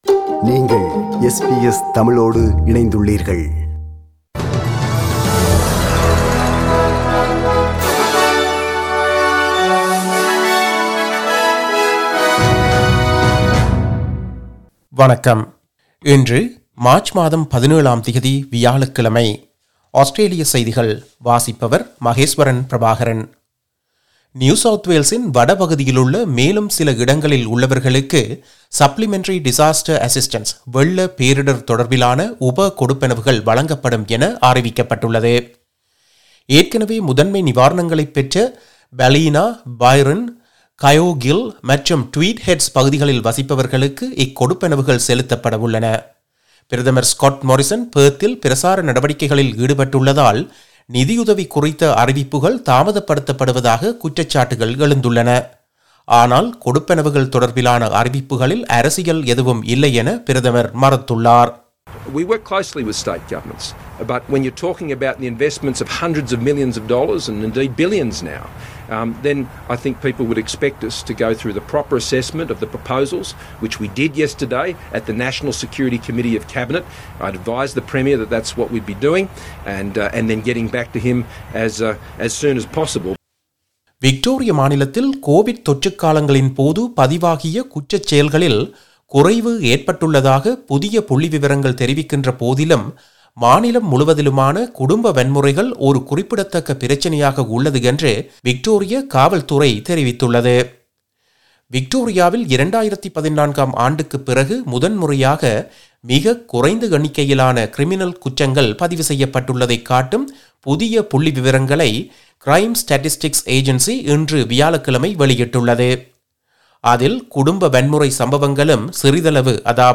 Australian news bulletin for Thursday 17 March 2022.